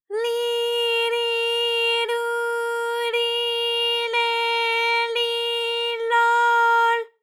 ALYS-DB-001-JPN - First Japanese UTAU vocal library of ALYS.
li_li_lu_li_le_li_lo_l.wav